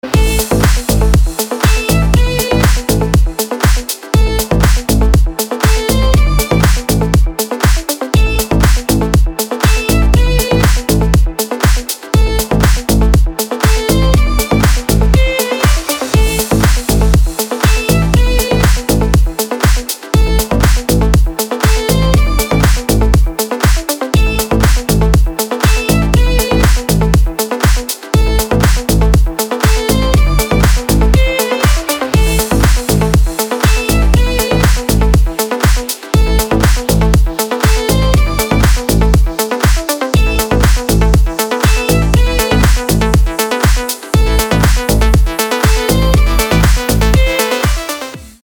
• Качество: 320, Stereo
красивые
dance
спокойные
без слов
скрипка